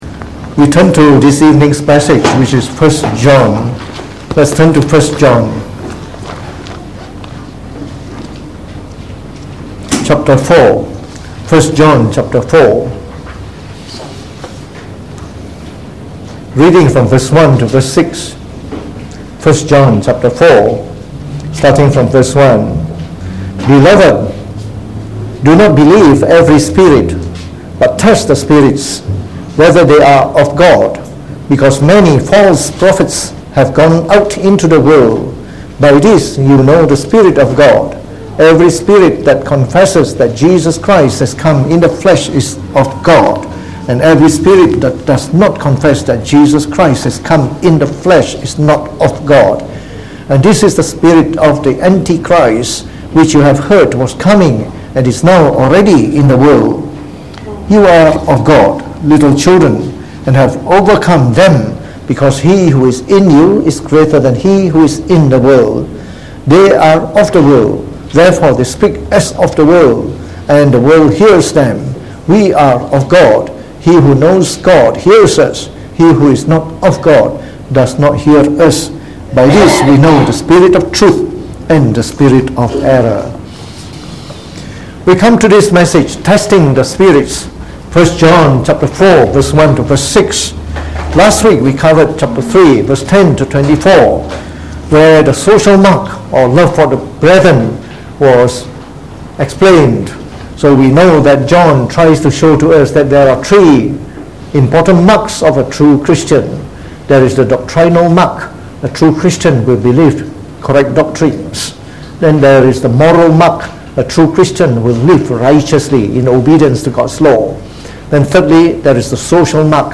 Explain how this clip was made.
From our series on the Epistle of 1 John delivered in the Evening Service